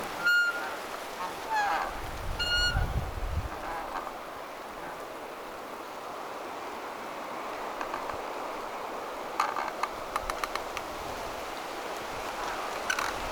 hiljaisempia narinoita myös,
tuollaiselta se kurkilauta
kuulosti läheltä
tuollaiselta_se_teltan_kurkilauta_kuulosti_lahelta.mp3